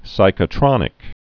(sīkə-trŏnĭk)